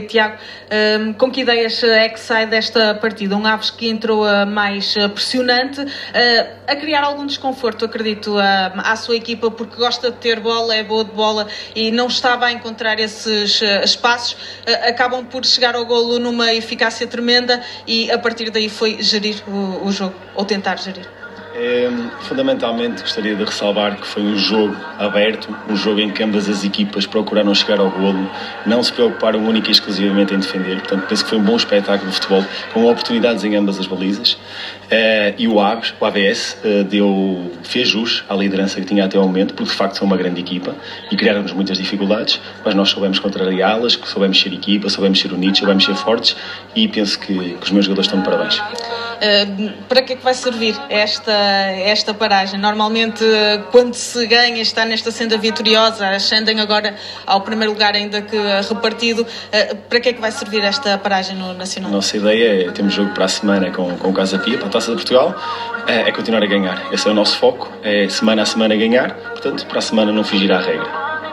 Na flash-interview realizada no final do encontro com o AVS, para a 11.ª jornada da Liga Portugal Sabseg